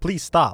Update Voice Overs for Amplification & Normalisation
please stop.wav